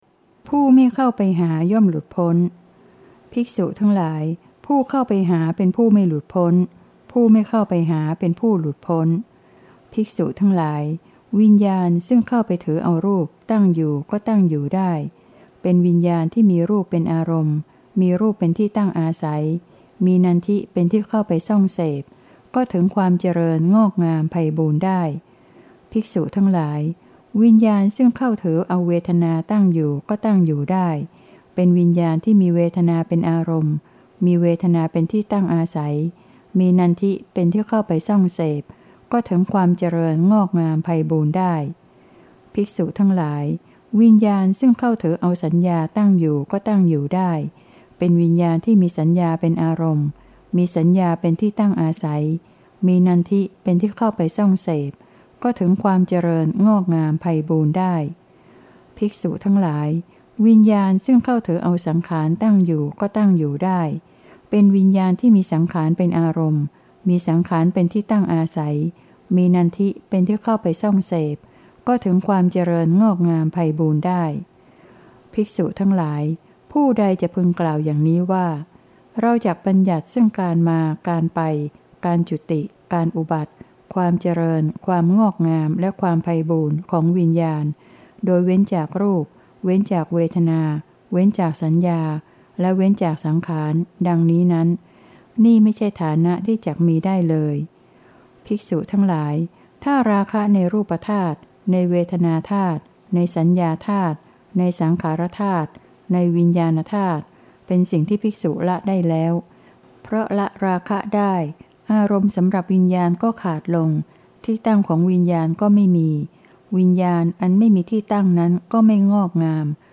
เสียงอ่าน